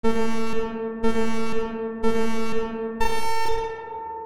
Race Start Countdown
Race or round start countdown with reverb beeps, 3, 2, 1, go style.
countdown.ogg